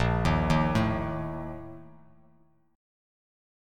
A#M7sus4 Chord